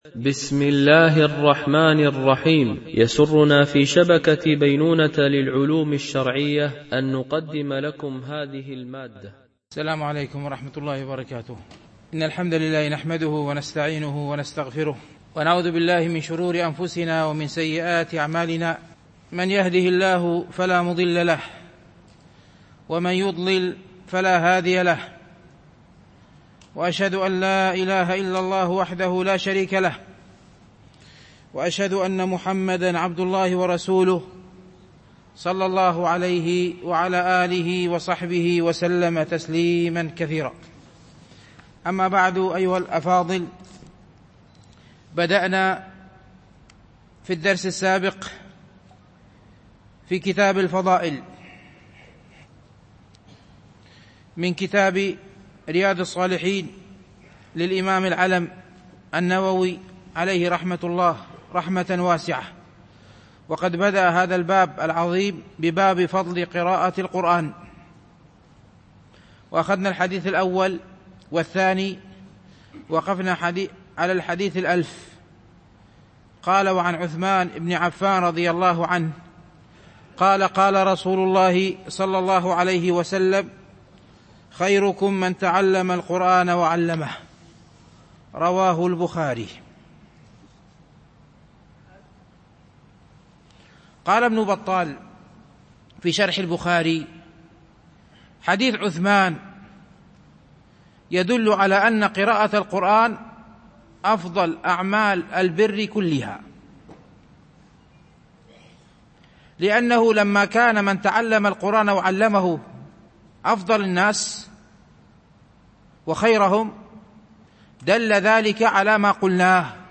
شرح رياض الصالحين – الدرس 259 ( الحديث 1000 – 1003 )